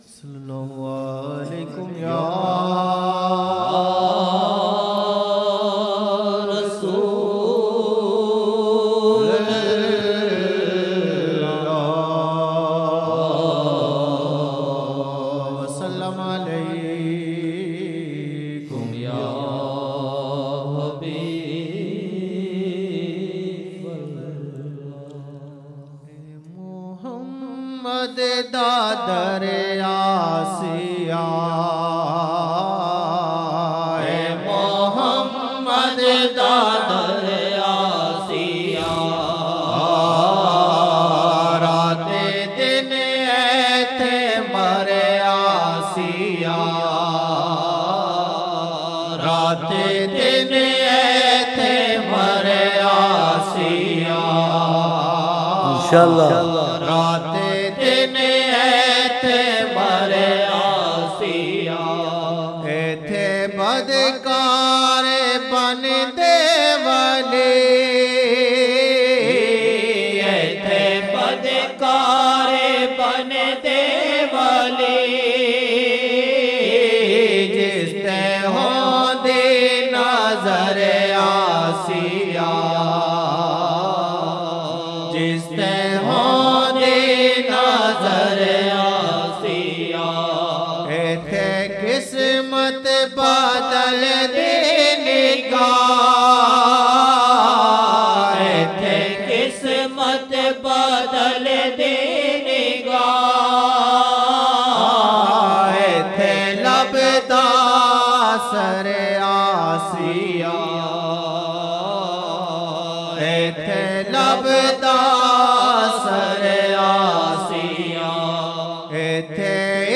Ae Muhammad ﷺ ka dar aasiya Raat din aithay mar aasiya 2007-01-07 Asr 07 Jan 2007 Old Naat Shareef